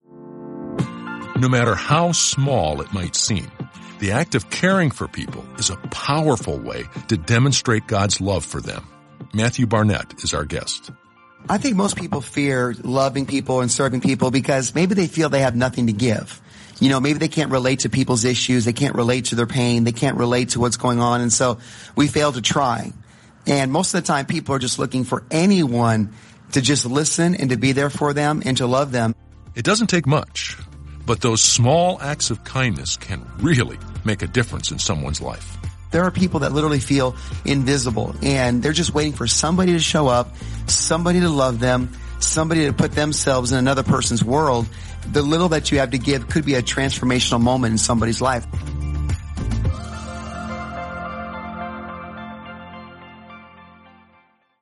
Each day contains a short (1–2 minute) audio message that ties into the journey theme.